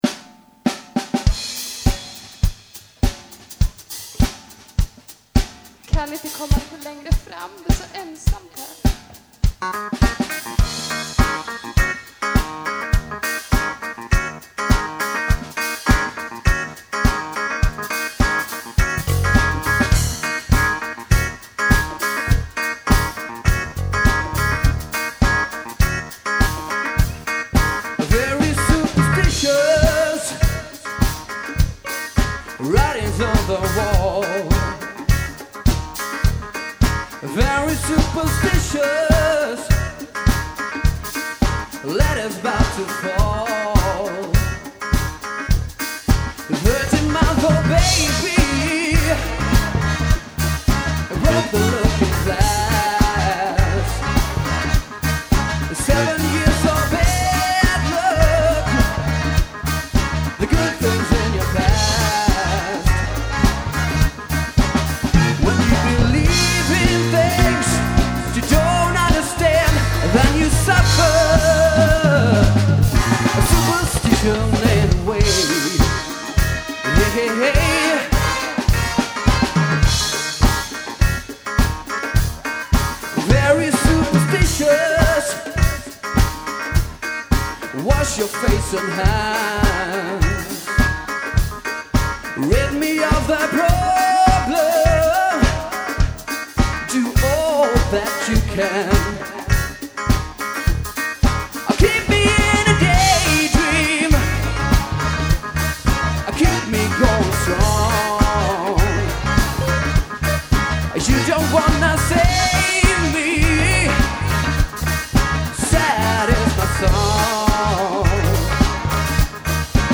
Plats: Utomhus, Ryd
Tillställning: Studentiaden 2000
Percussion
Gitarr
Inte minst med hjälp utomordentligt taktiskt wha-wha-spel :-)